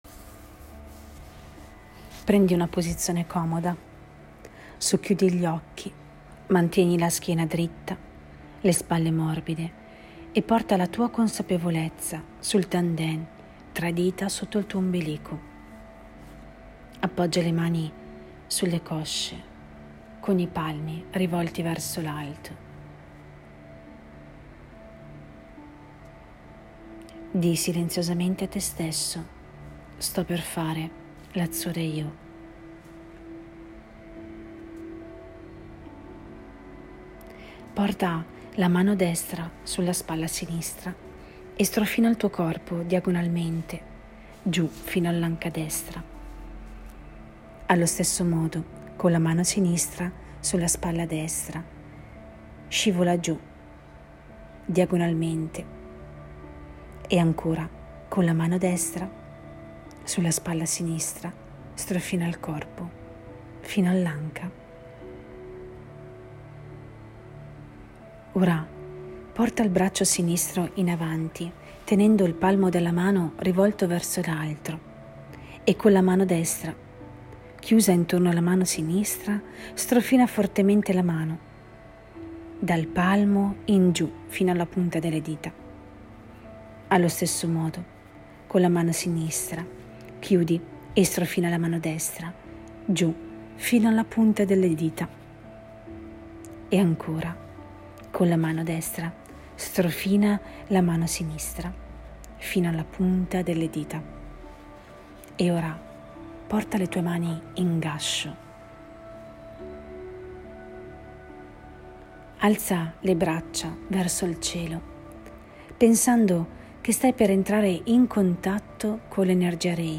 MEDITAZIONE GUIDATA
Per voi ho registrato il metodo Reiki Hatsurei Ho (meditazione guidata)